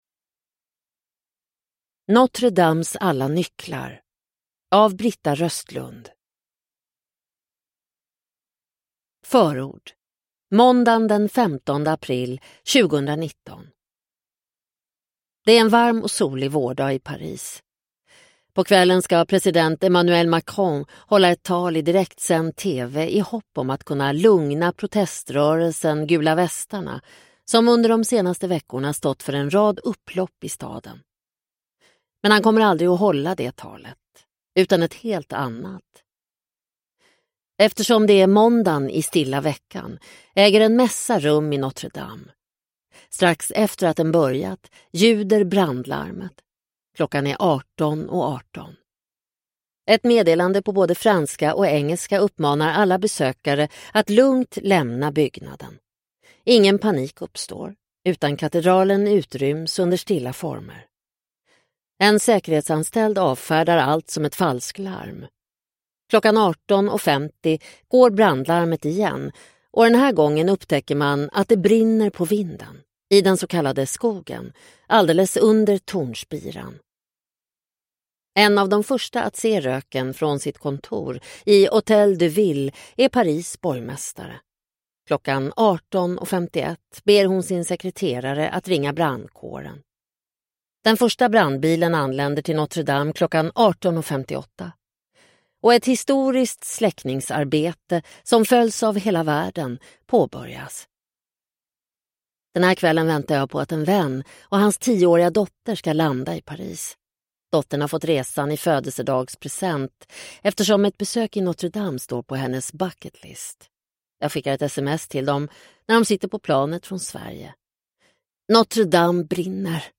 Notre-Dames alla nycklar – Ljudbok – Laddas ner